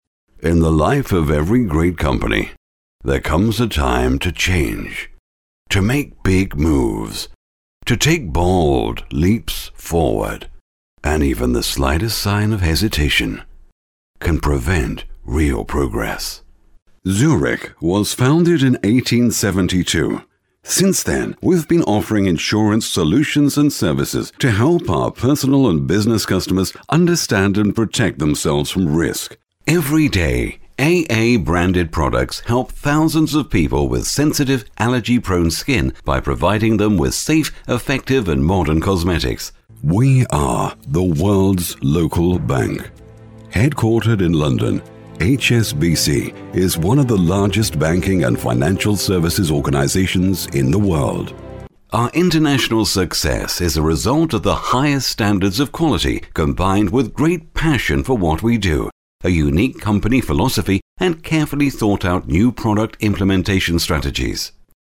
Sprechprobe: Industrie (Muttersprache):
About me: I am a professional voiceover with a rich but clear, resonant, authorative voice. I have a standard English accent, but am very flexible in styles. I have my own broadcast quality studio with a Neumann U87 & TLM 103 microphones and offer fast turnarounds on recording.